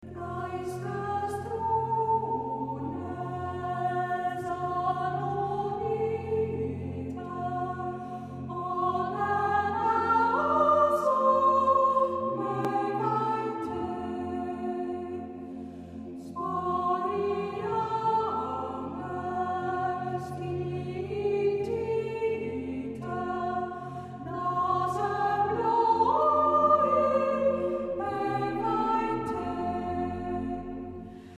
contribute to a contemplative atmosphere